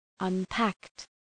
Προφορά
{ʌn’pækt}